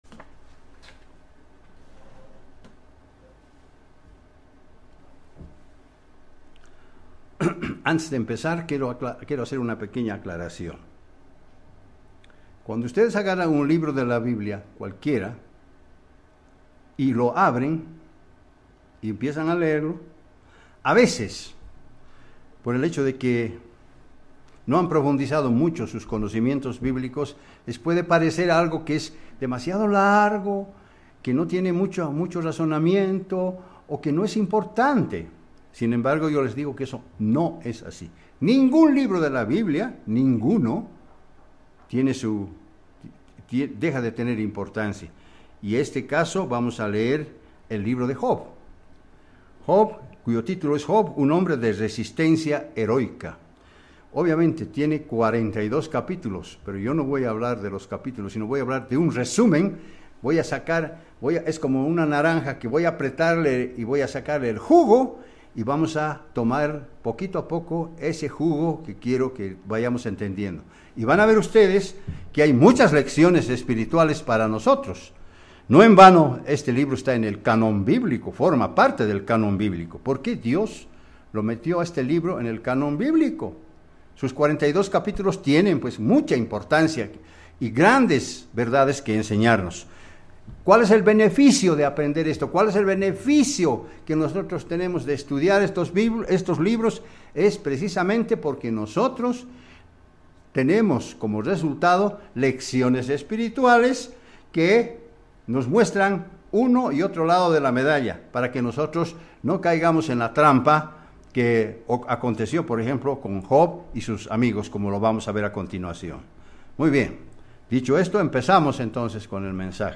Mensaje entregado el 2 de febrero de 2019.
Given in La Paz